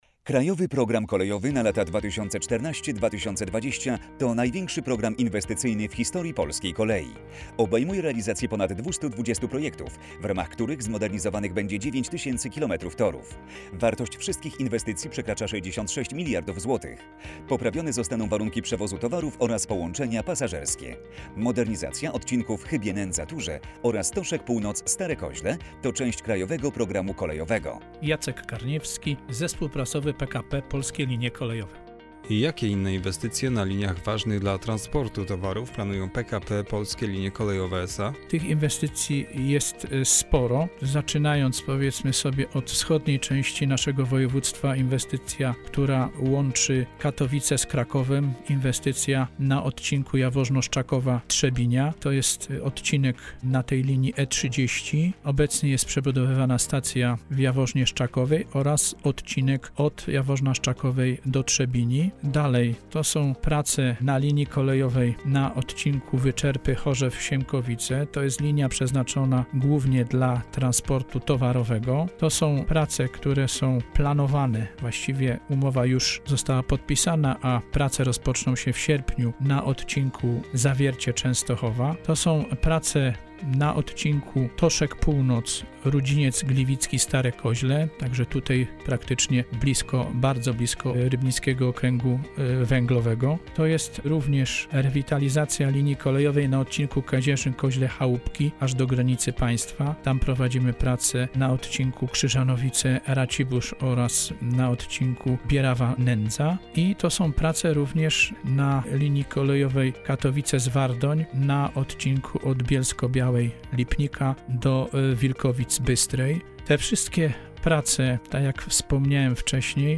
Audycje radiowe - kwiecień 2018 r, odc. 5/2